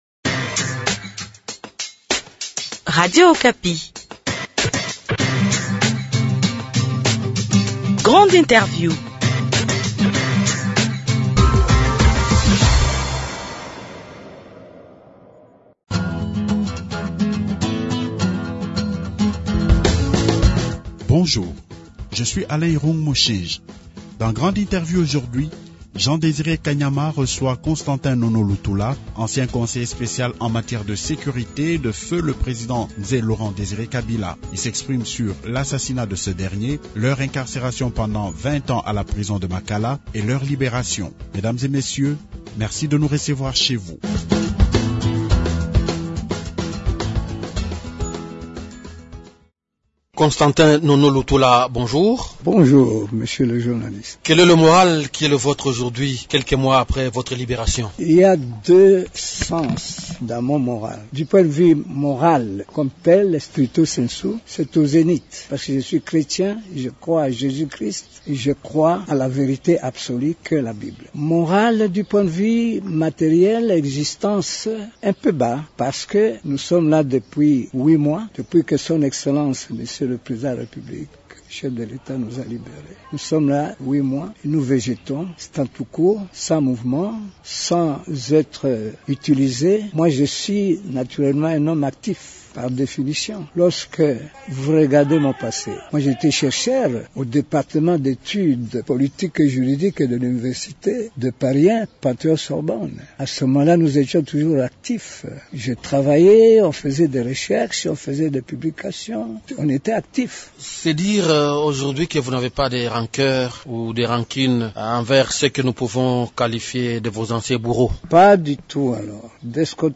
Grande interview